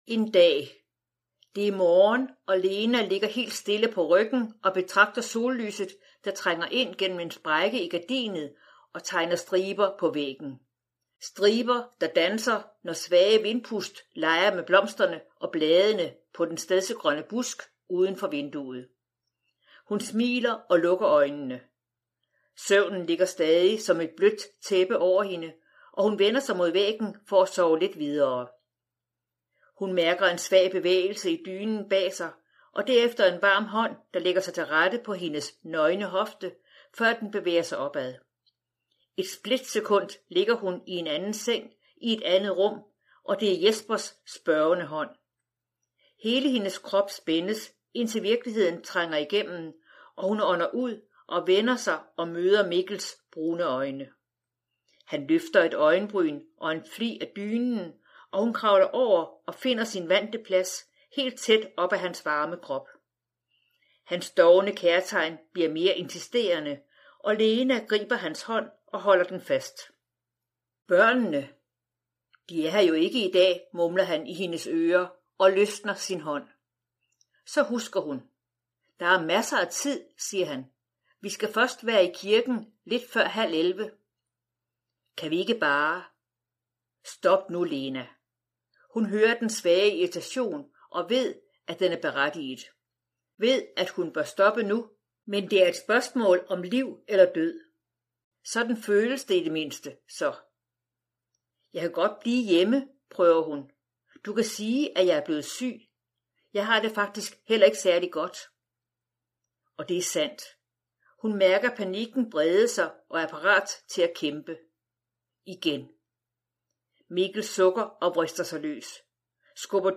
Hør et uddrag af Brud Brud Format MP3 Forfatter Inge Bøgel Lassen Bog Lydbog E-bog 149,95 kr.